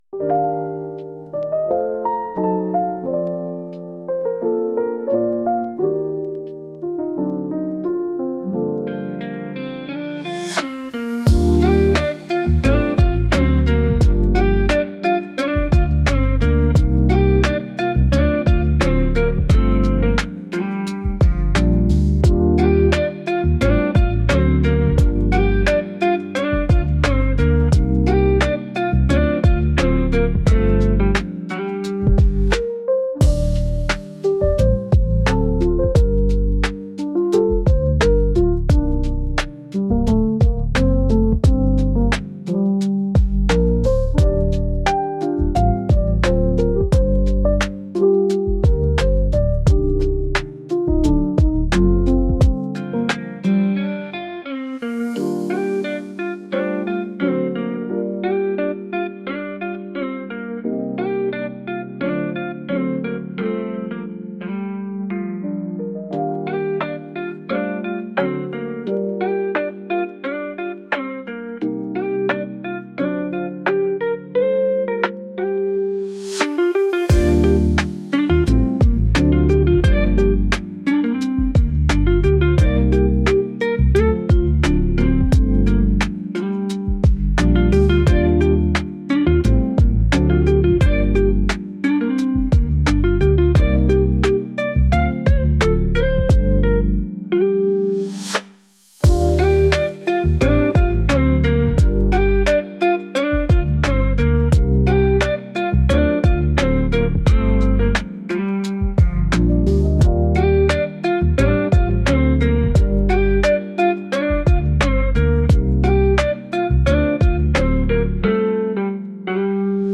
誰かを待っているまったりした曲です。